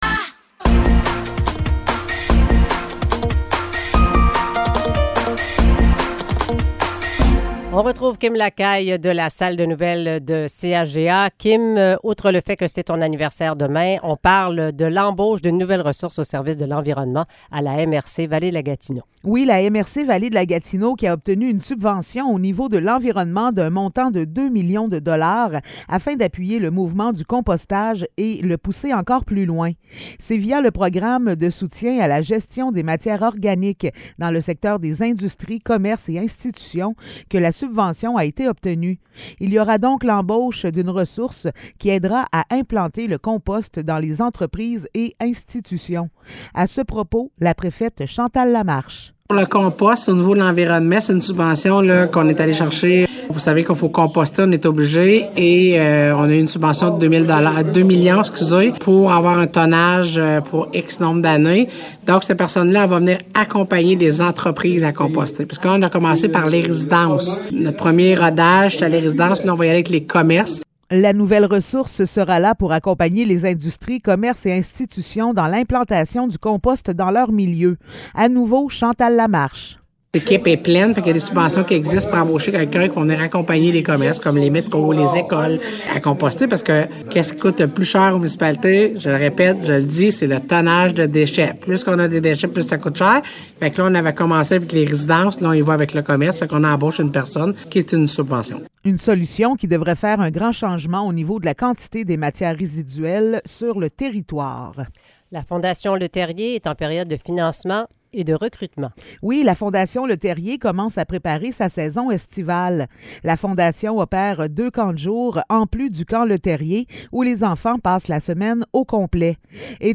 Nouvelles locales - 24 mars 2023 - 7 h